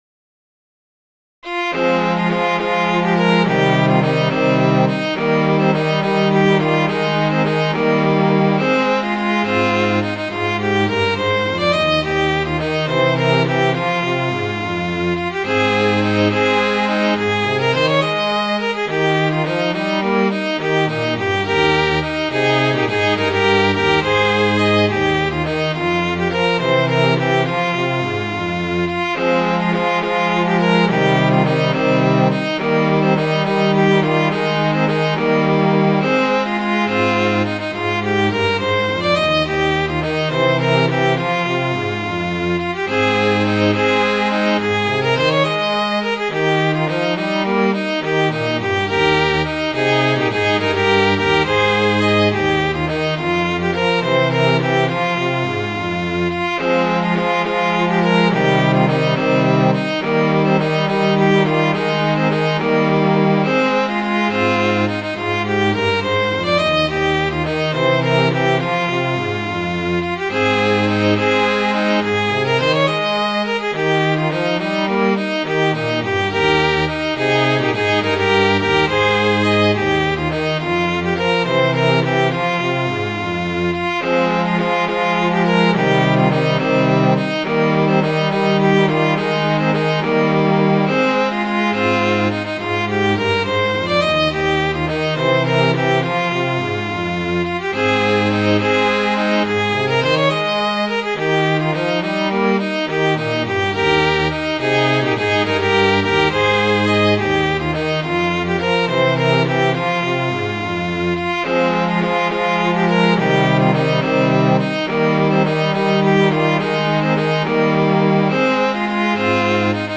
Midi File, Lyrics and Information to The Gaberlunzie Man